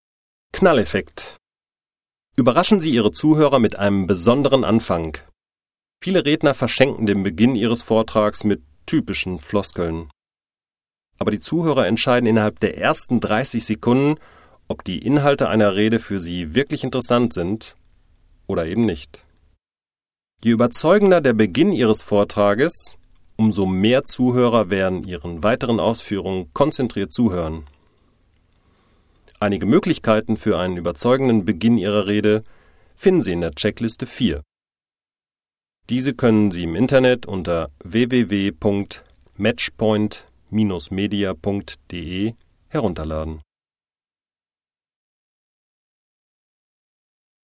Downloads zu dem Rhetorik Hörbuch “Rhetorik von A - Z”